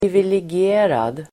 Uttal: [privilegi'e:rad]